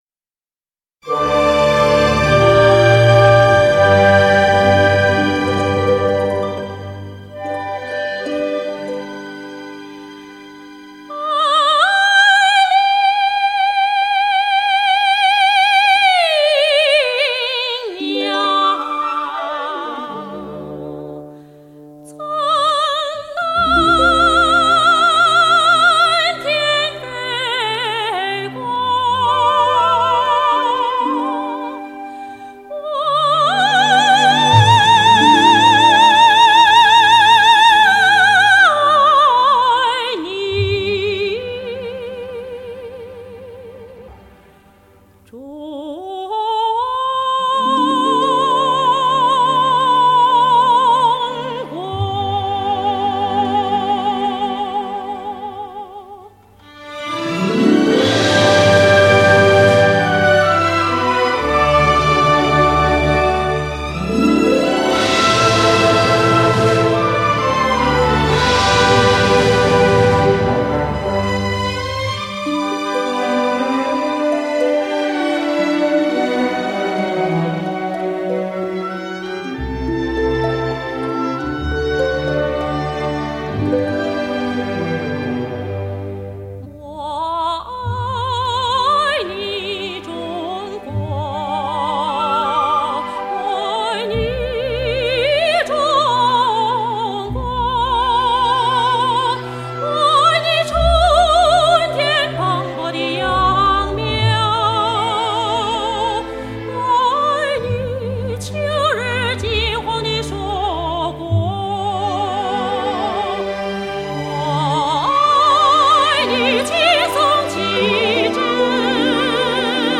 24K金碟 HDCD版